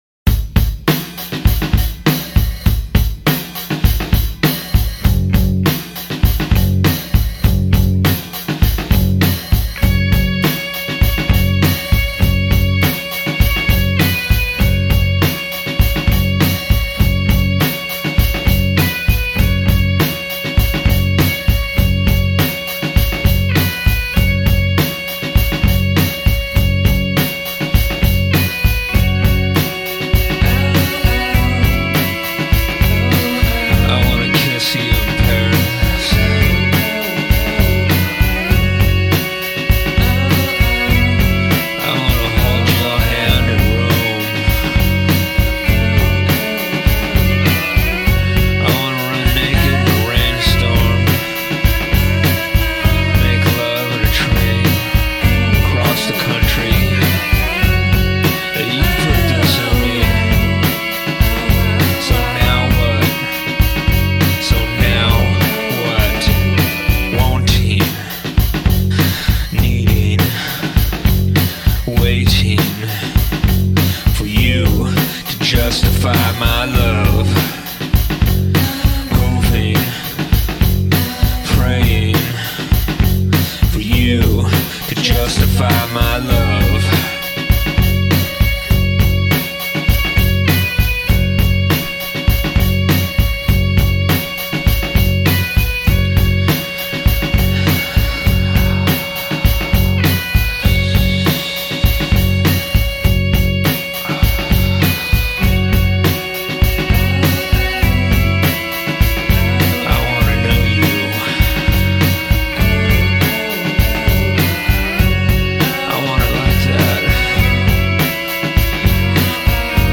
vocals, Ebo guitar
drums
bass